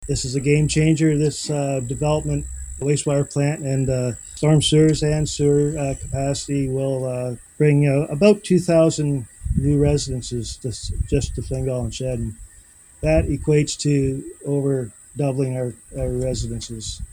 In an announcement Wednesday morning in Shedden, the Township of Southwold is the recipient of almost $28 million in provincial funding.
Mayor Grant Jones called it a game-changer.